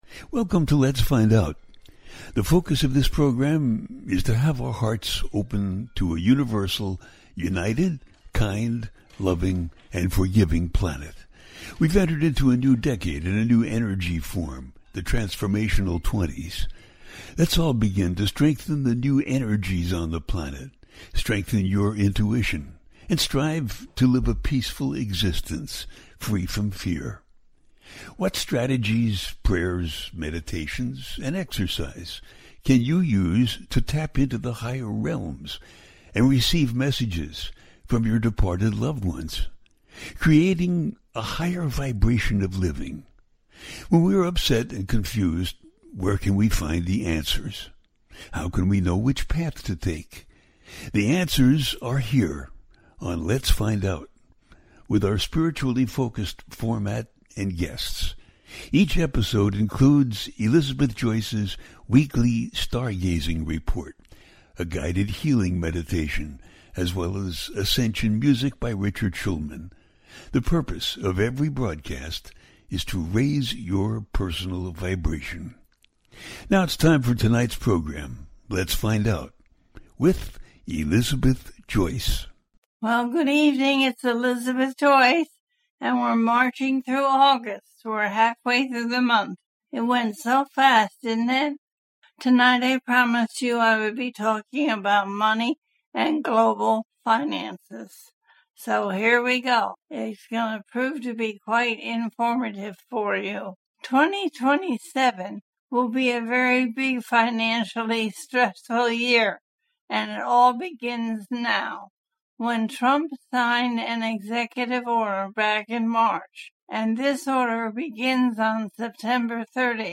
Finances And Astrology Continued - A teaching show
The listener can call in to ask a question on the air.
Each show ends with a guided meditation.